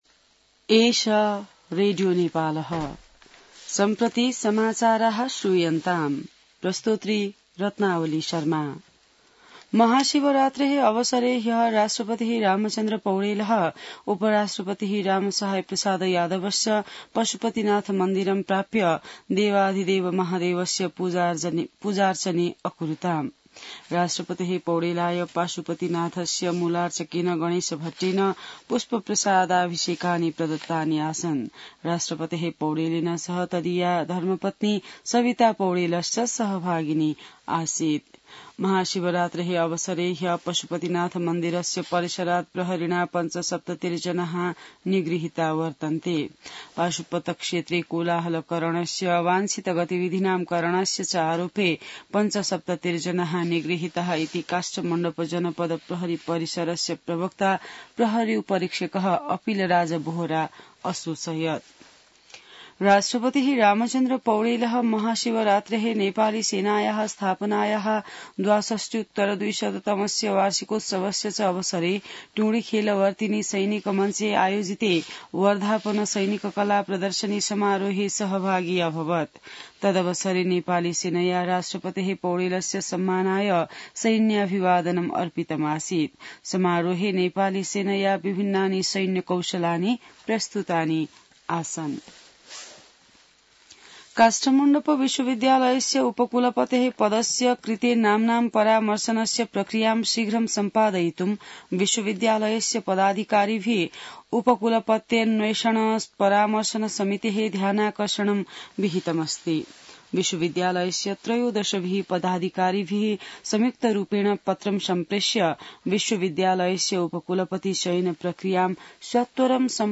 संस्कृत समाचार : १६ फागुन , २०८१